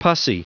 Prononciation du mot pursy en anglais (fichier audio)
Prononciation du mot : pursy